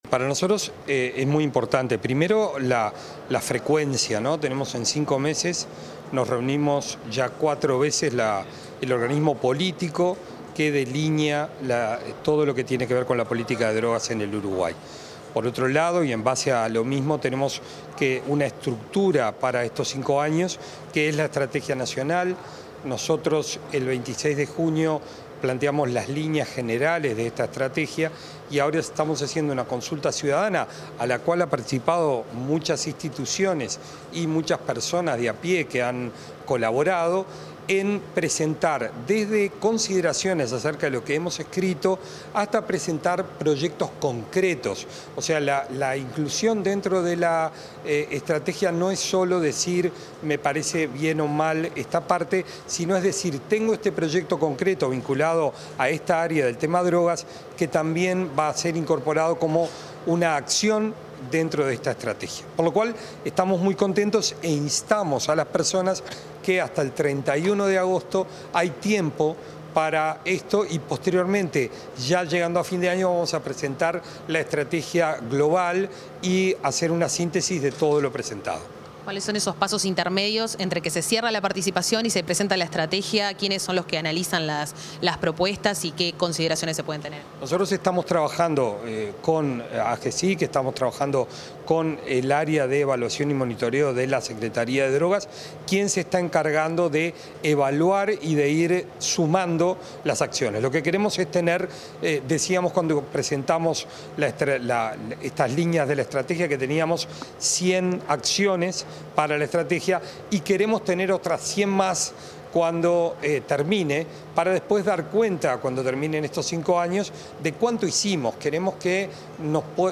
Declaraciones del secretario nacional de Drogas, Gabriel Rossi
Tras la reunión mensual de la Junta Nacional de Drogas, se expresó el titular de la Secretaría Nacional de Drogas, Gabriel Rossi.